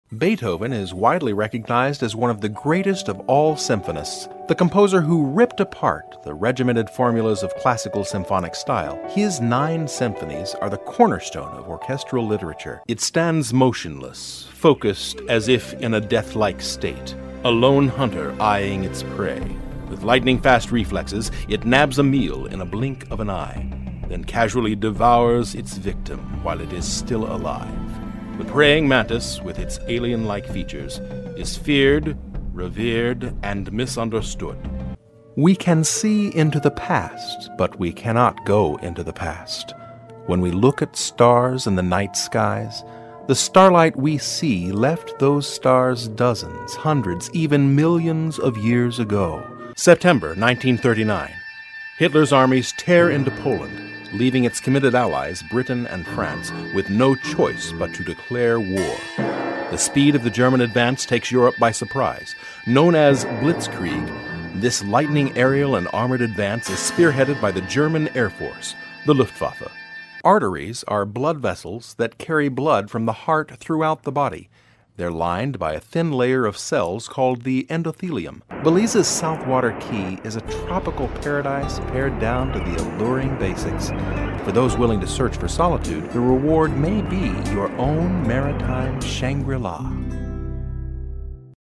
Voice over, Unaccented American English, Narrator, Corporate, Commercial, Private Recording Studio, clear, friendly, natural
Sprechprobe: eLearning (Muttersprache):
A natural, warm, clear, personable voice, adaptable to many types of projects! Can be vibrant and fun, with a wry sense of humor and lighthearted attitude; or firm and direct with a strong voice of authority.